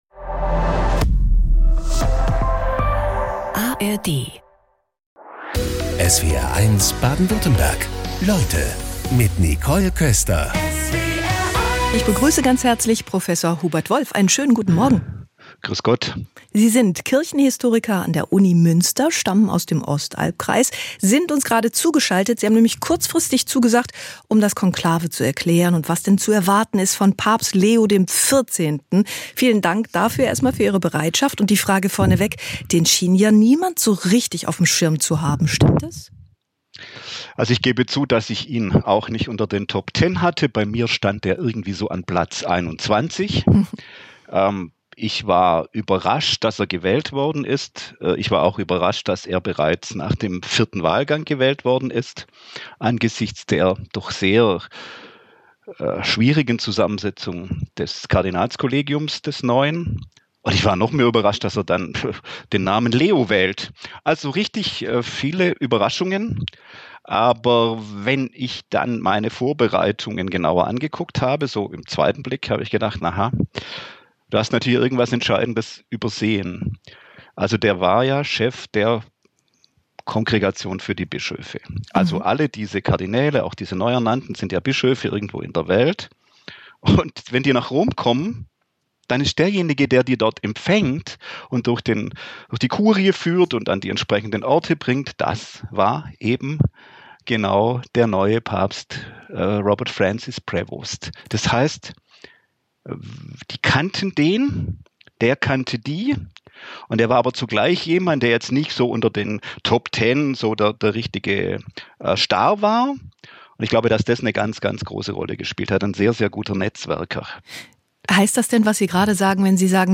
Jeden Tag talken unsere SWR1 Leute-Moderator:innen in Baden-Württemberg und Rheinland-Pfalz mit interessanten und spannenden Gästen im Studio.